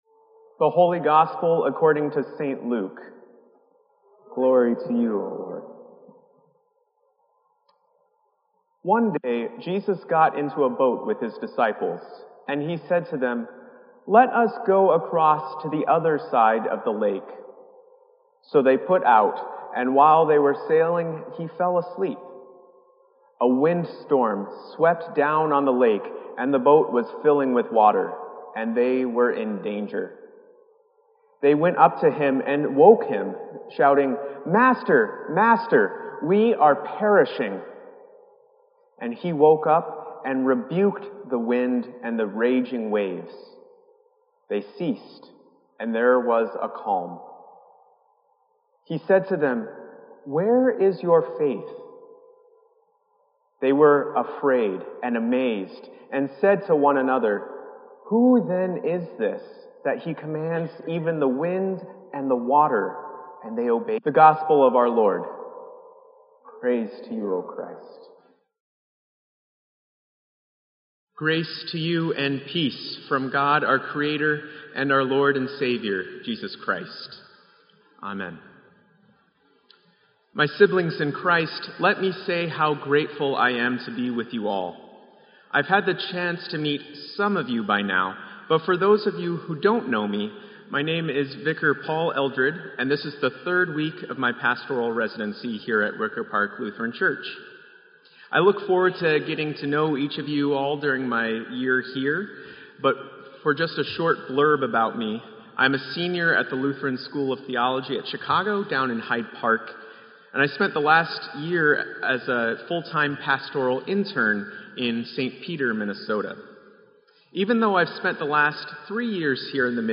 Wicker Park Lutheran Church
Sermon_9_18_16.mp3